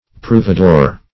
Search Result for " provedore" : The Collaborative International Dictionary of English v.0.48: Provedore \Prov"e*dore\, n. [Cf. Sp. proveedor.